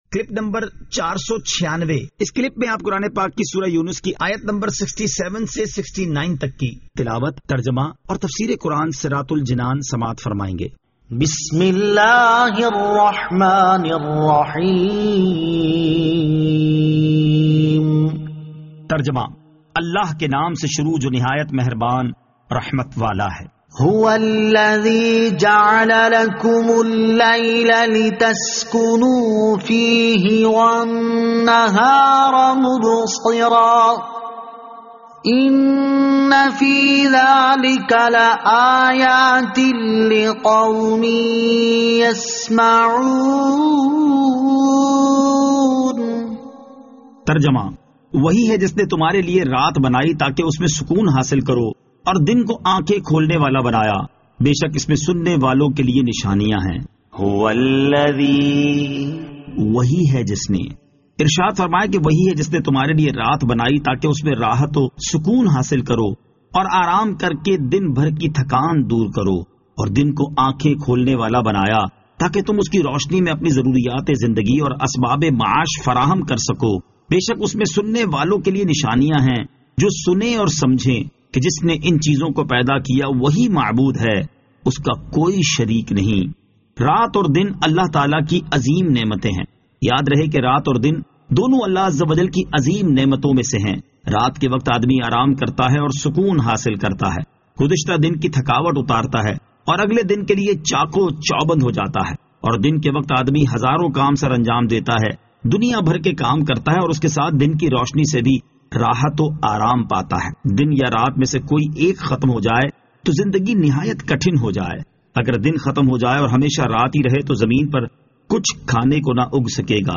Surah Yunus Ayat 67 To 69 Tilawat , Tarjama , Tafseer